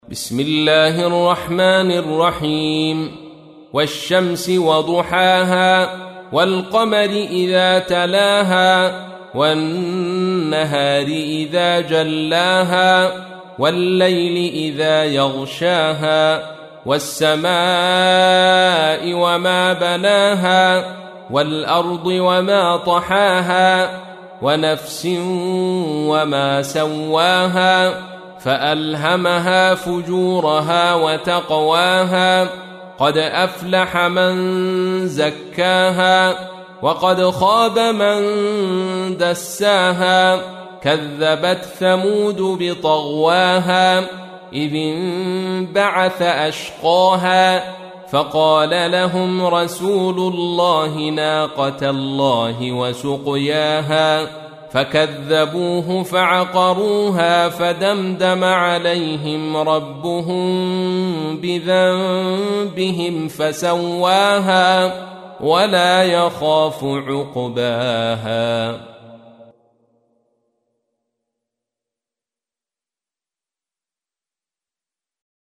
91. سورة الشمس / القارئ